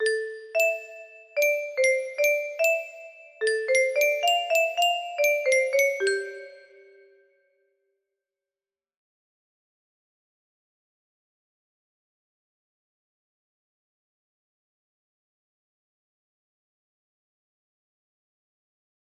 please I don't know the song name or the lyrics music box melody